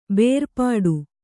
♪ bērpāḍu